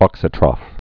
(ôksə-trŏf, -trōf)